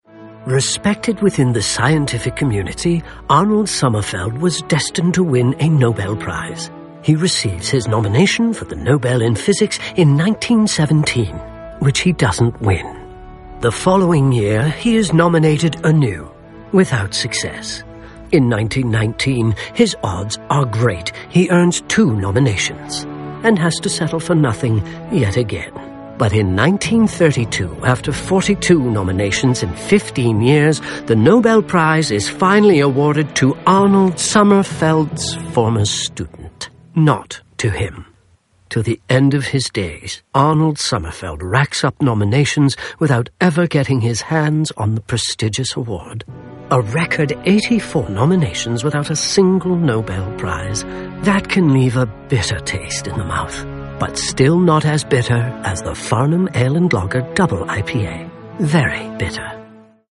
SilverRadio - Single